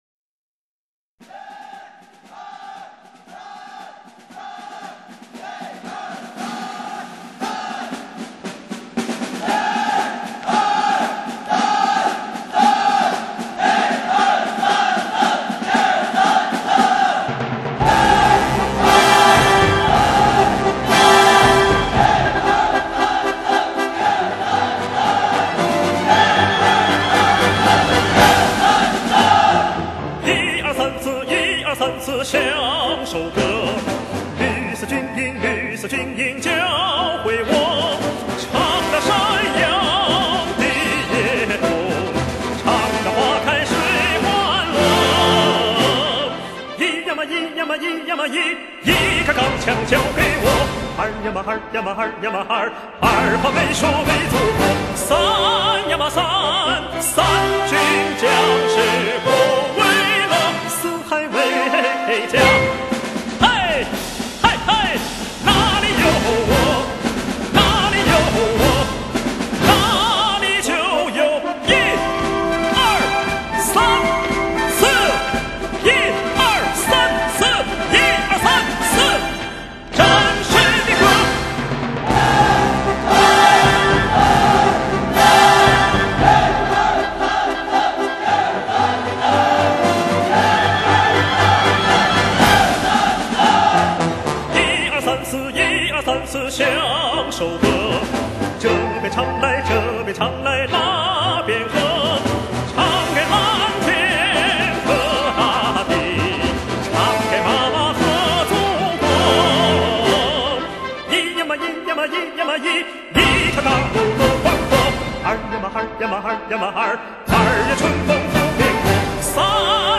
版    本:爱国主义军歌名曲